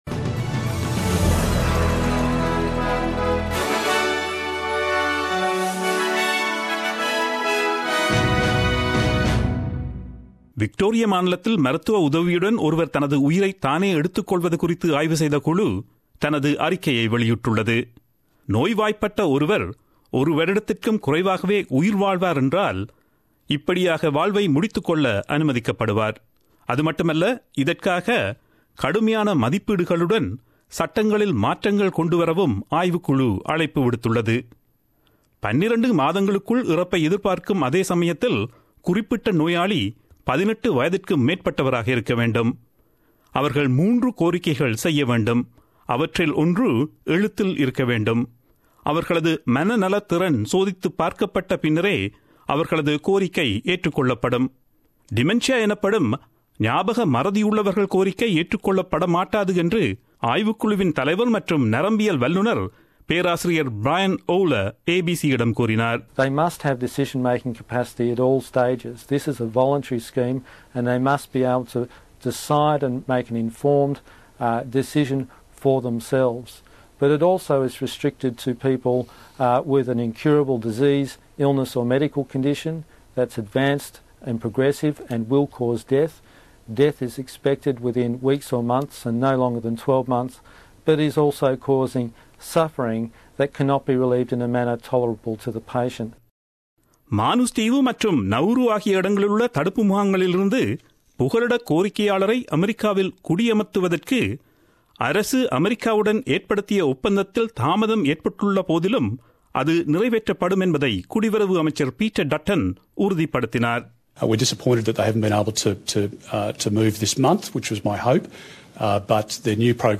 Australian news bulletin aired on Friday 21 July 2017 at 8pm.